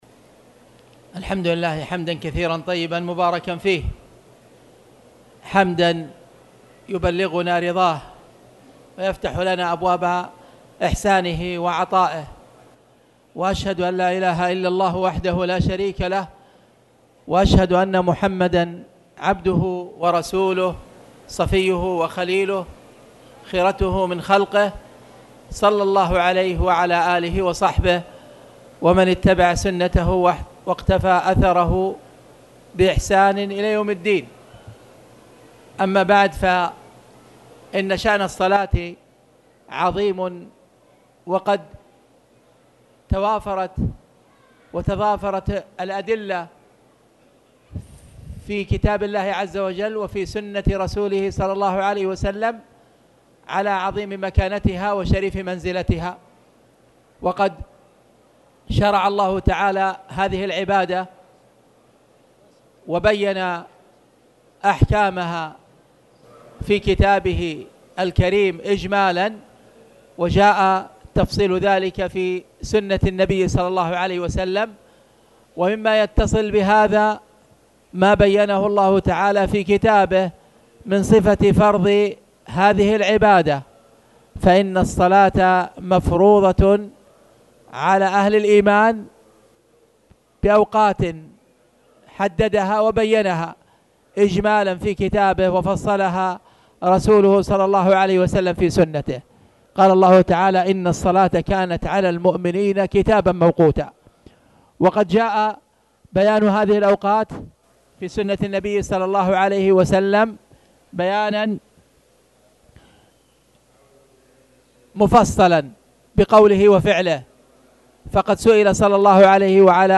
تاريخ النشر ٦ محرم ١٤٣٨ هـ المكان: المسجد الحرام الشيخ: خالد بن عبدالله المصلح خالد بن عبدالله المصلح كتاب الصلاة-باب المواقيت